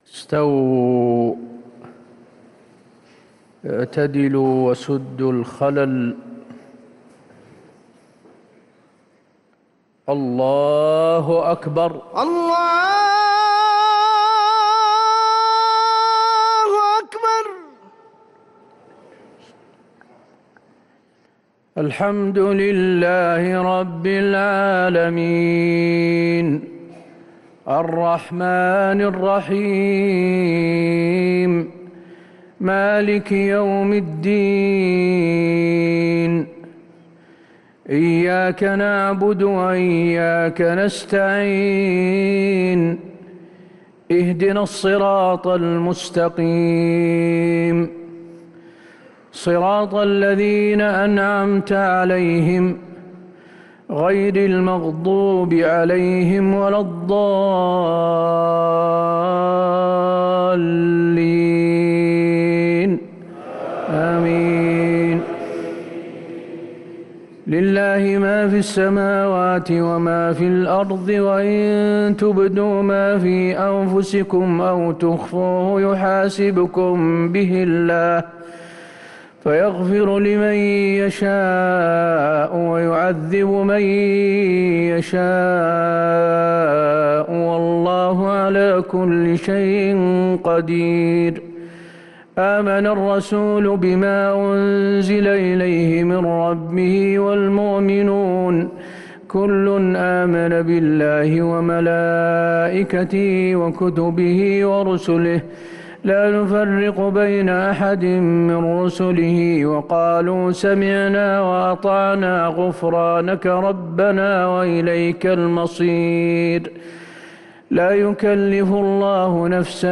صلاة العشاء للقارئ حسين آل الشيخ 6 ربيع الأول 1445 هـ